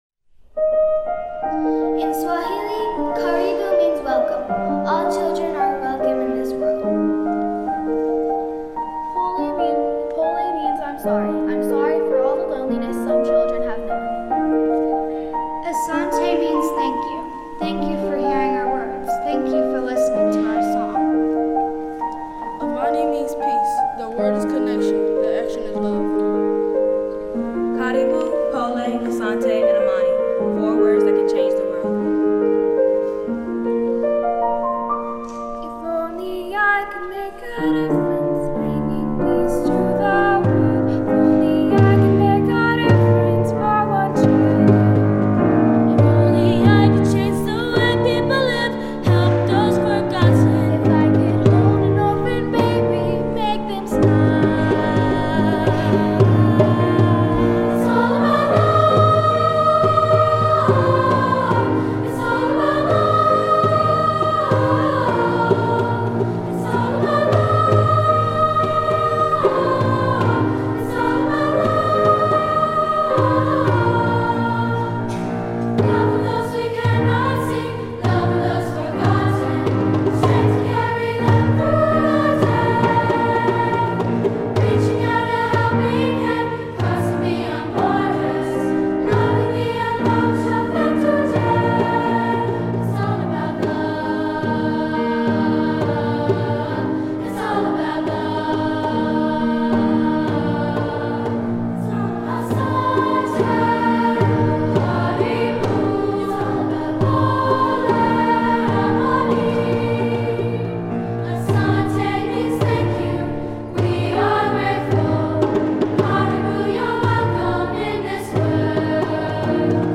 Voicing: Two-part equal; Solos; Speakers